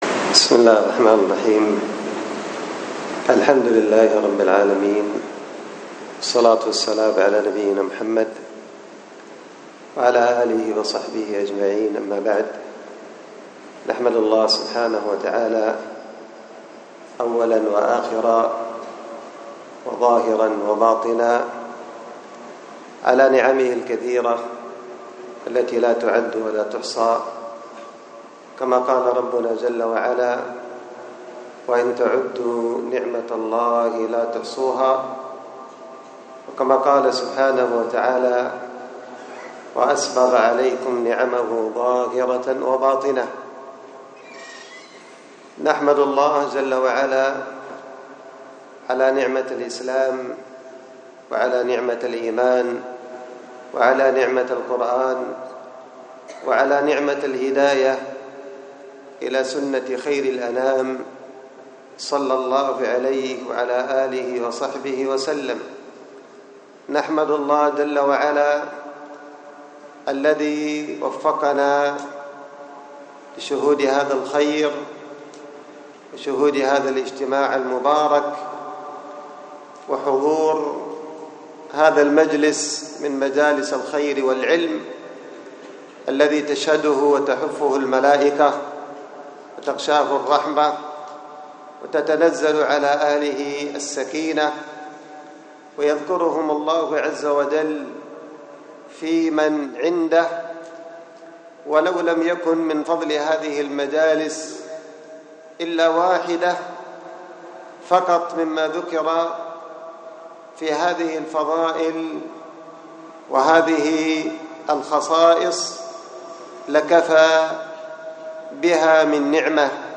الخطبة
وكانت بمسجد التقوى بدار الحديث بالشحر